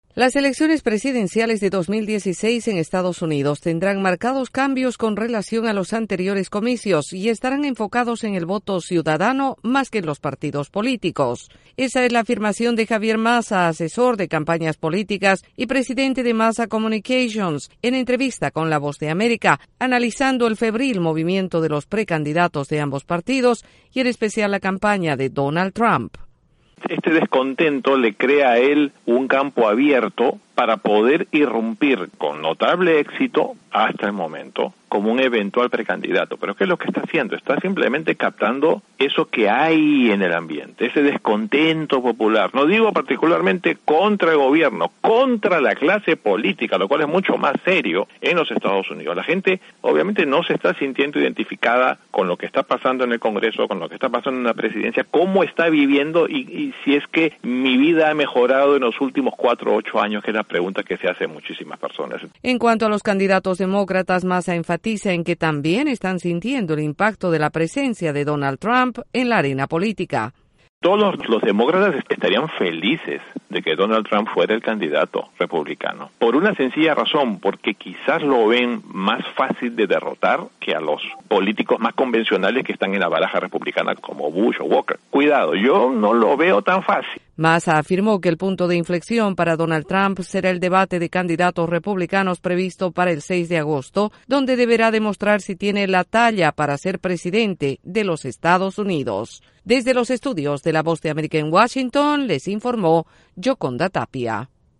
La política estadounidense está agitada con más de una veintena de candidatos de los partidos demócrata y republicano, y hay uno que preocupa a ambos. Desde la Voz de América en Washington informe